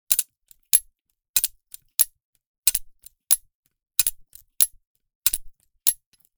Download Free Garden Sound Effects | Gfx Sounds
Rotating-sprinkler-loop-garden-outdoor-4.mp3